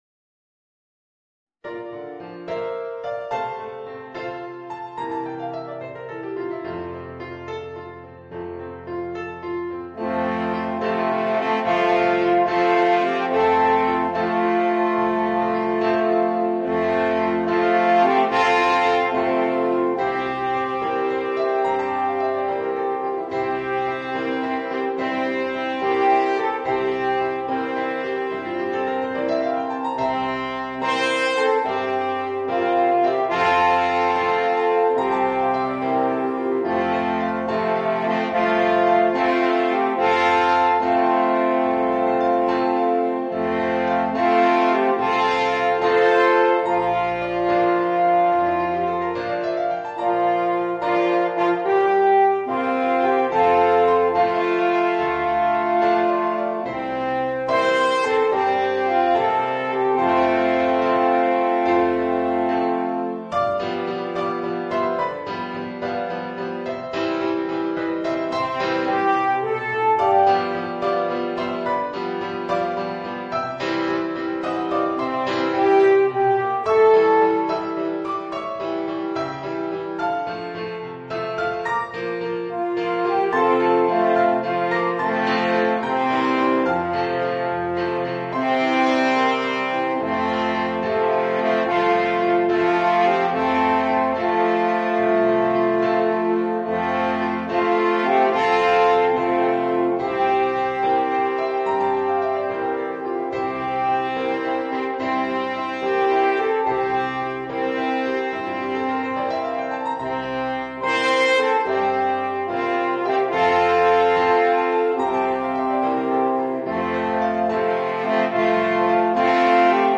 Voicing: 2 Alphorns and Piano